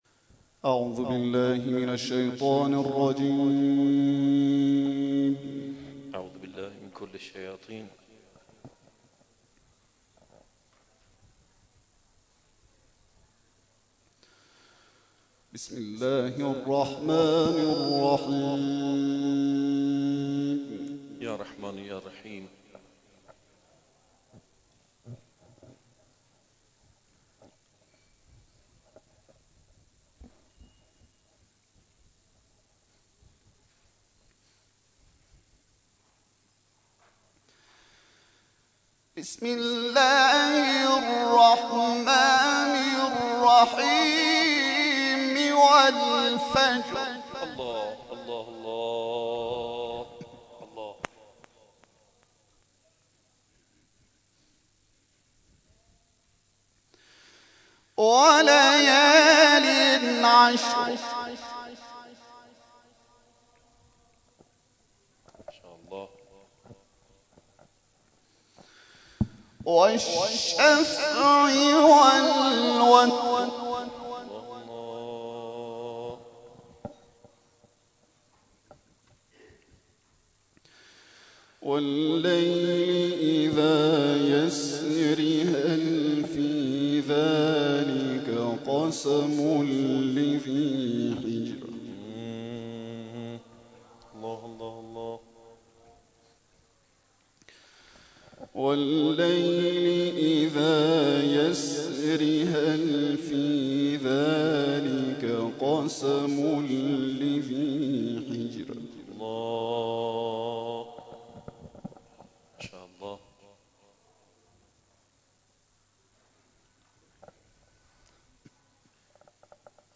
به گزارش خبرگزاری بین‌المللی قرآن(ایکنا)، کرسی‌های تلاوت مجلسی به همت دارالقرآن نفحات‌القرآن در راستای پیروی از منویات مقام معظم رهبری درباره برپایی محافلی برای استماع قرآن از فروردین ۱۳۹۵ آغاز شد و در این هفته به مجلس صد و نود و نهم خود رسید.